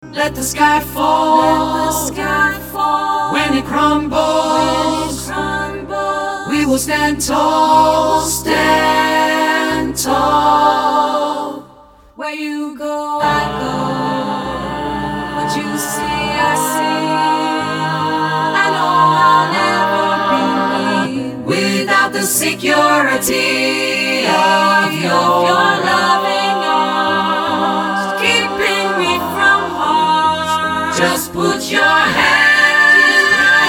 Een krachtig en sfeervol koorarrangement
De baritonpartij kan desgewenst worden weggelaten.
A powerful and atmospheric choral arrangement
The baritone line may be omitted if needed.